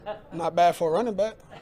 not bad for a running back Meme Sound Effect